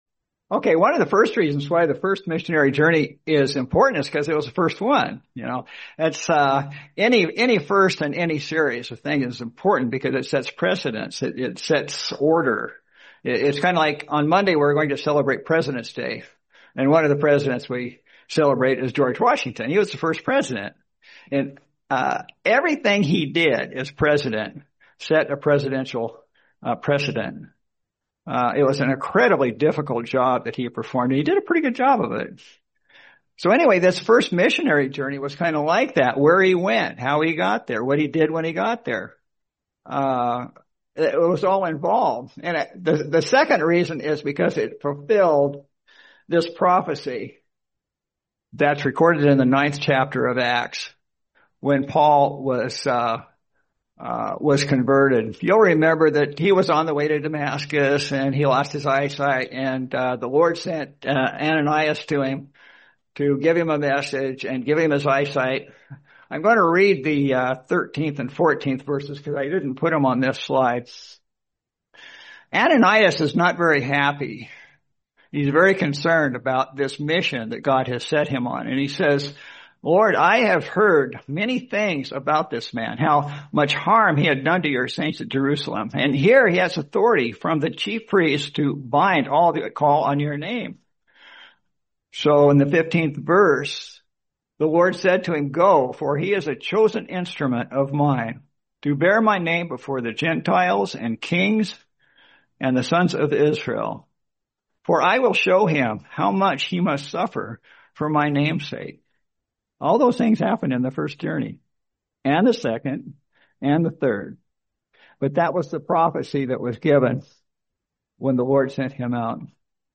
Series: 2026 Sacramento Convention